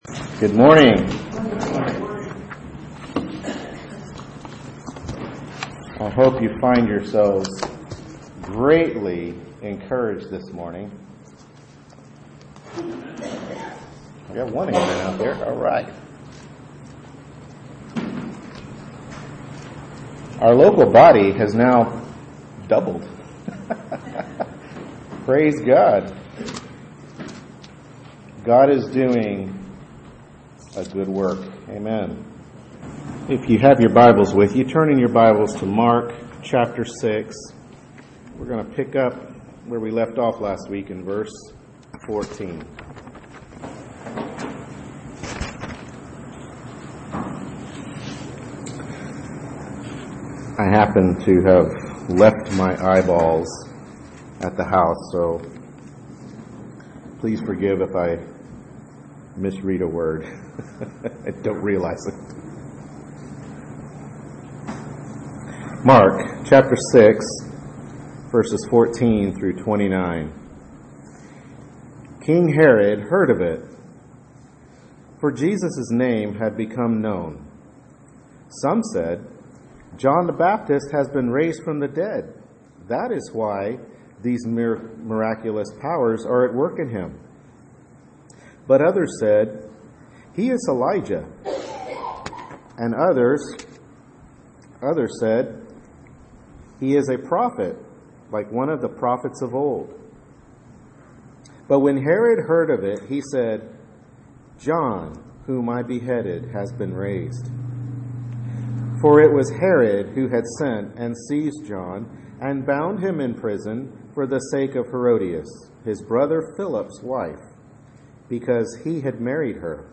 Mark 6:14-29 Service Type: Morning Worship Service Bible Text